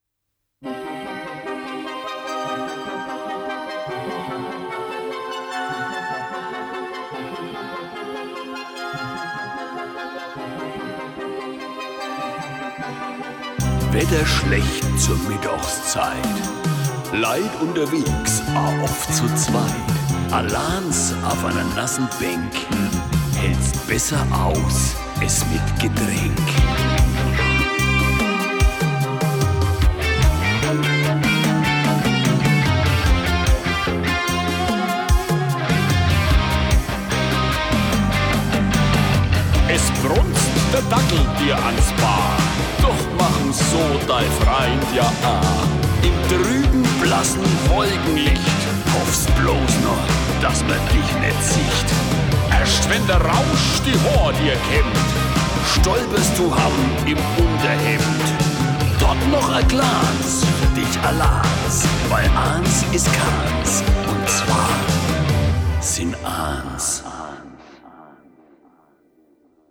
Genre: Rock.